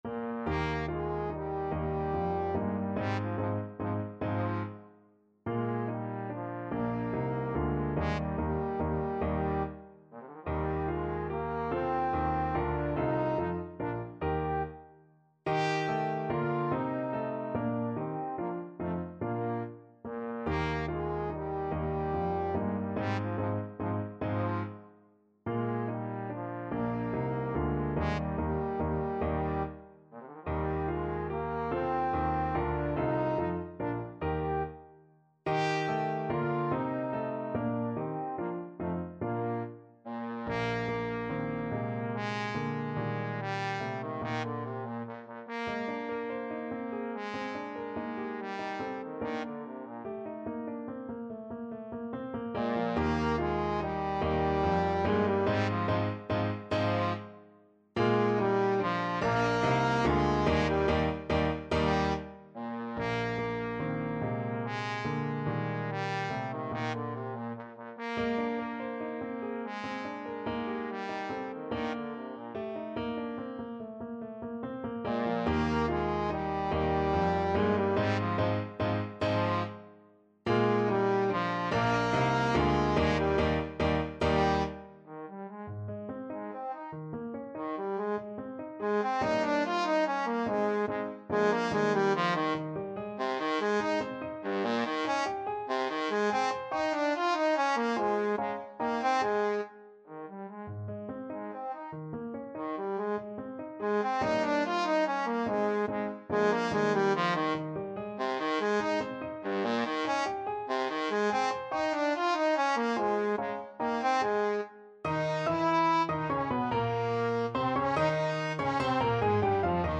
Trombone
Eb major (Sounding Pitch) (View more Eb major Music for Trombone )
Moderato =c.144
3/4 (View more 3/4 Music)
G3-G5
Classical (View more Classical Trombone Music)